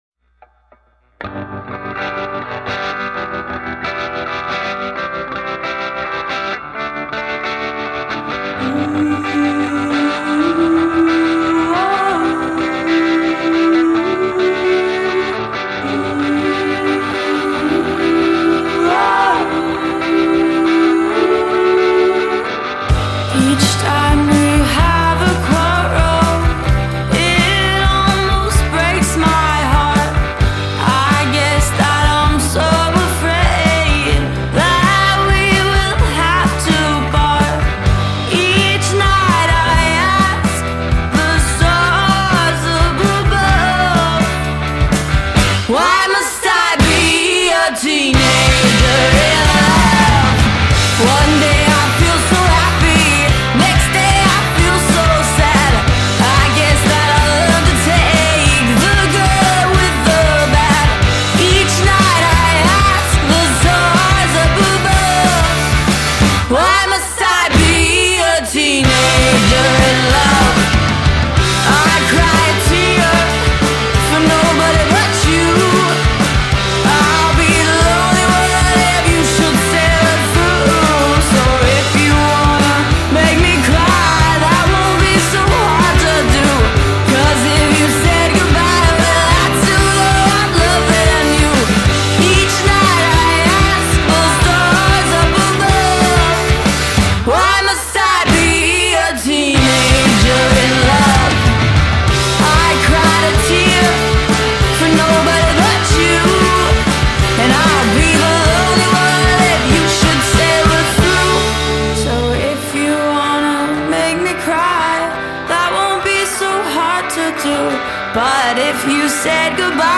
Nice neopunk cover